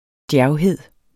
Udtale [ ˈdjæɐ̯wˌheðˀ ]